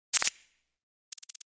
menu-edit-click.ogg